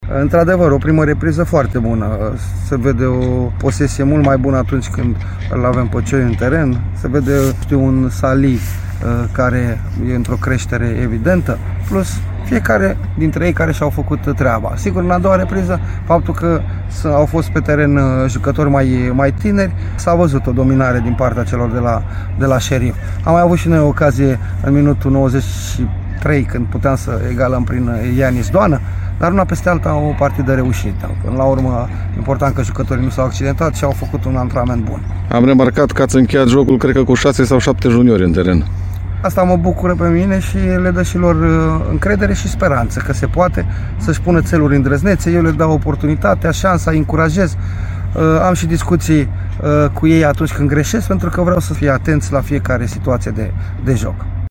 Antrenorul cărășenilor, Flavius Stoican, despre acest amical: